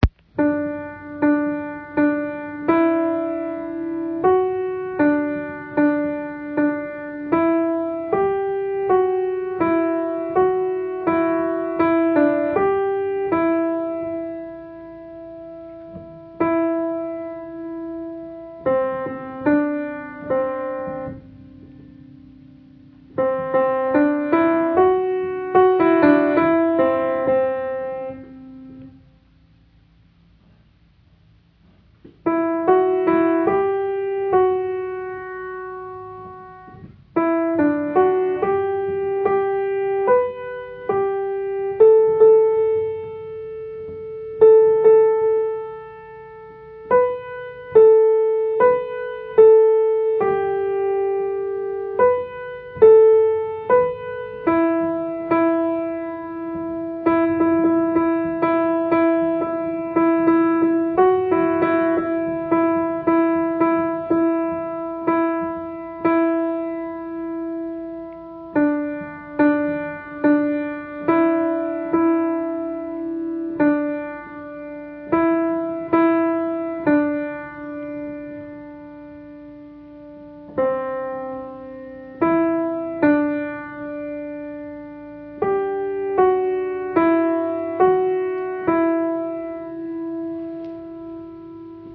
Répétition de la pičce musicale N° 804
ALTO                     1